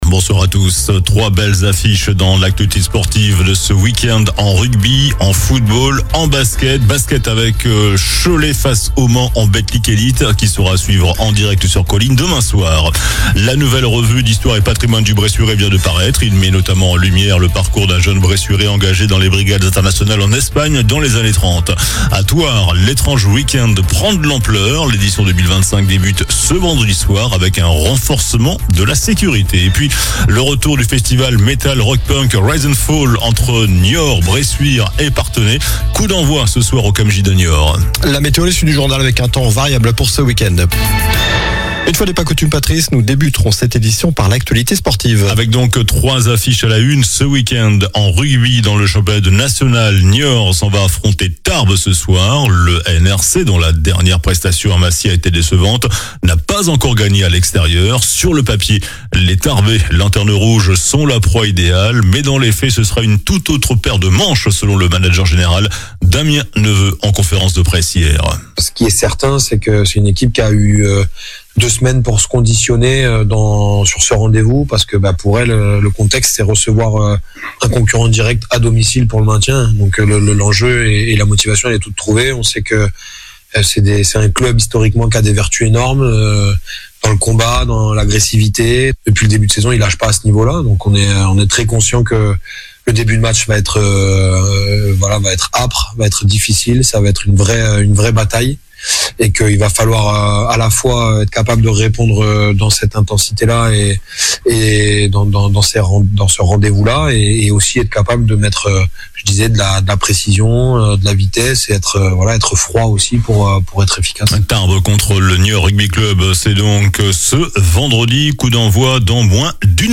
JOURNAL DU VENDREDI 31 OCTOBRE ( SOIR )